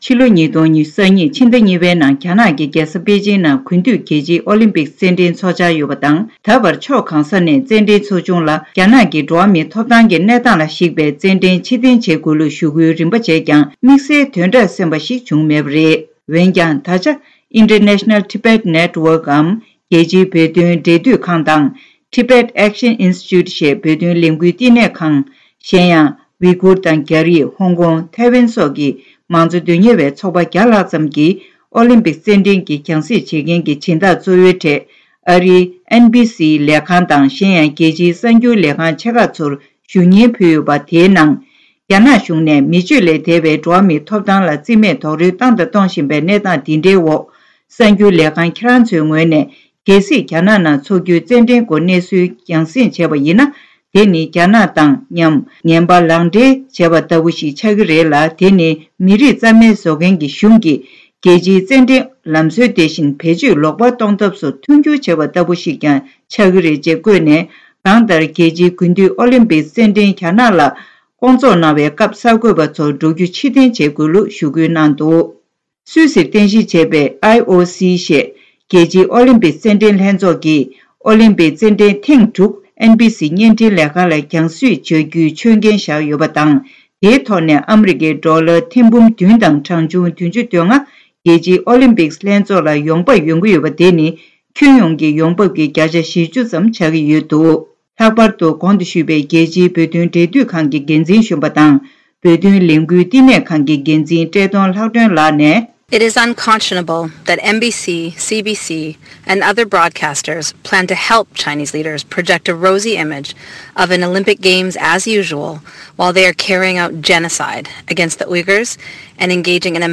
གསར་འགྱུར་དཔྱད་གཏམ